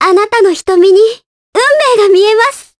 Ophelia-Vox_Victory_jp.wav